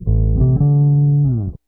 BASS 12.wav